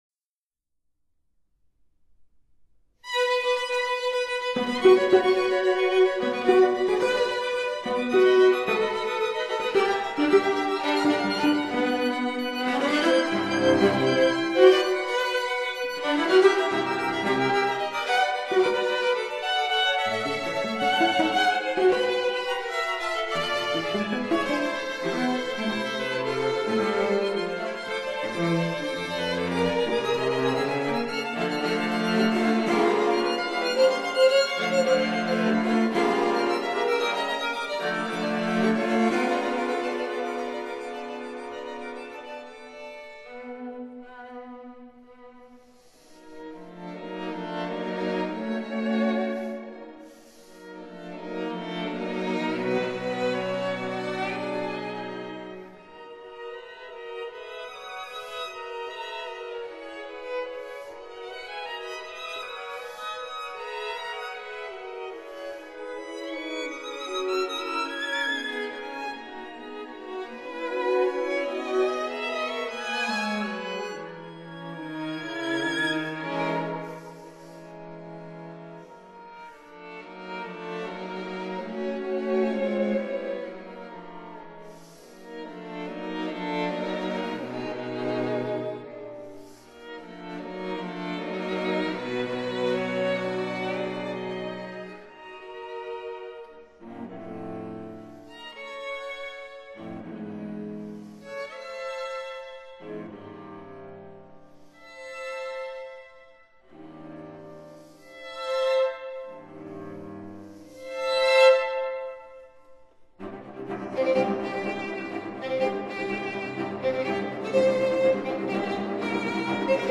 violin
viola
cello